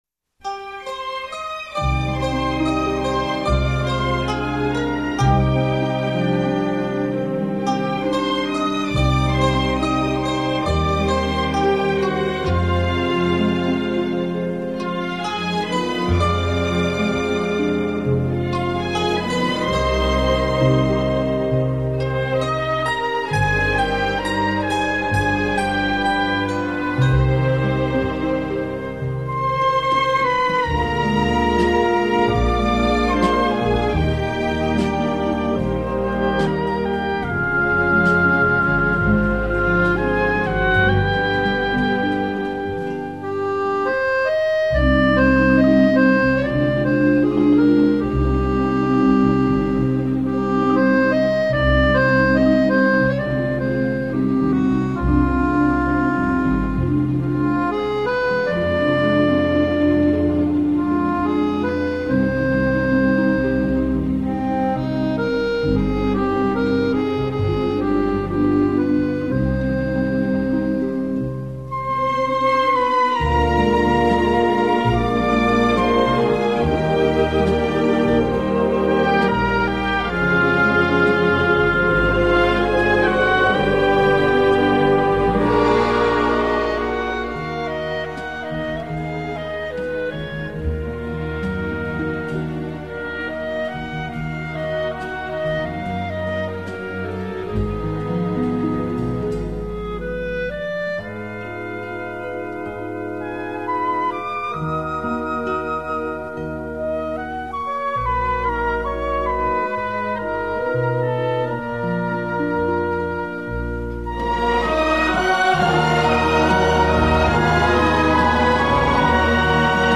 • Качество: 112, Stereo
грустные
спокойные
инструментальные